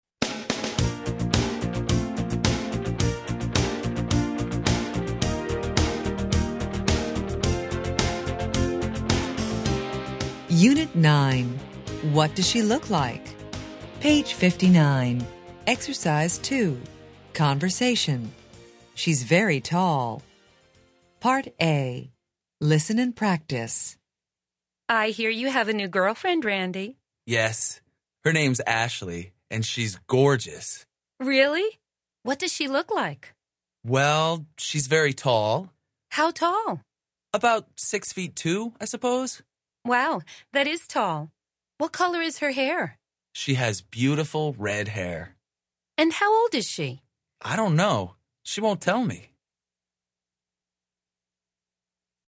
American English
Interchange Third Edition Level 1 Unit 9 Ex 2 Conversation Track 26 Students Book Student Arcade Self Study Audio